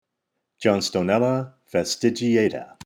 Pronunciation/Pronunciación:
John-sto-nél-la fas-ti-gi-à-ta